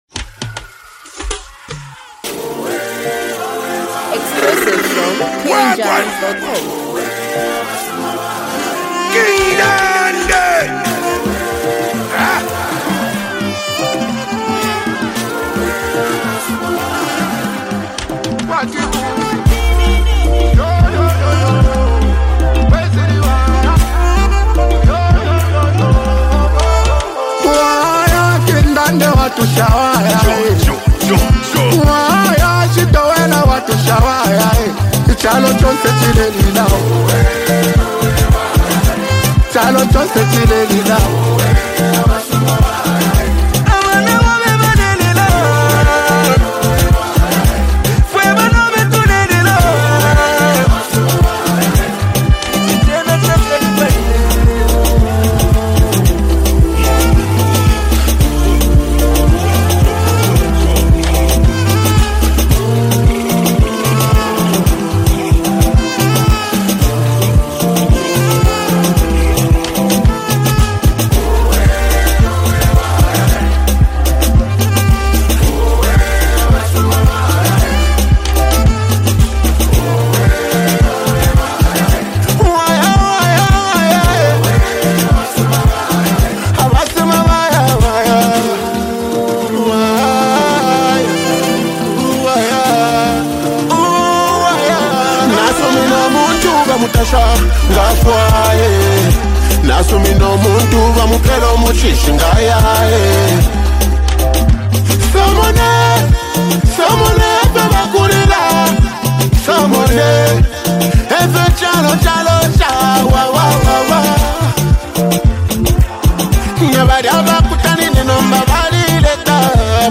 hype, emotional vibes, and a powerful hook
unique singing flow